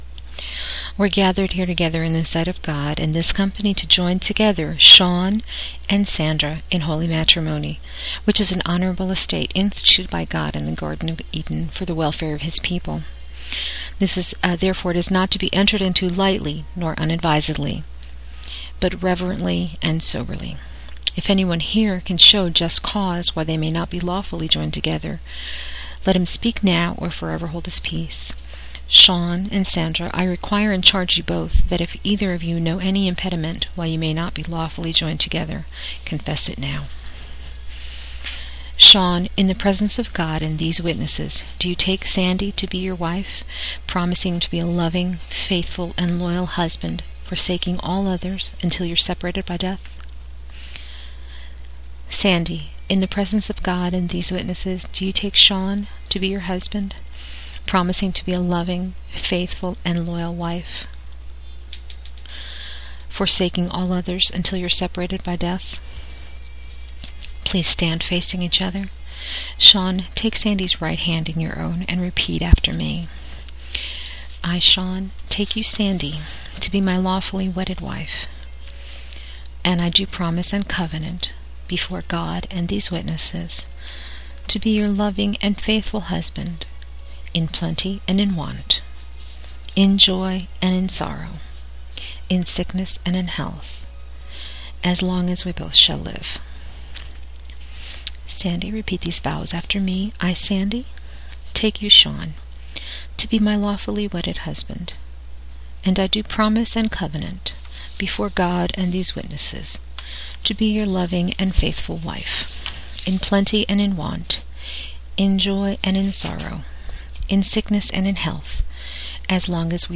This is only the most basic of ceremonies, including only what is required by law and a few Christian elements, because it was created for the training of new officiants. This was done in my office, not at a wedding venue, and there was, of course, no bridal couple, nor any guests.
Because of the limitations of my recording equipment, you will hear my breaths, etc. (Sorry!)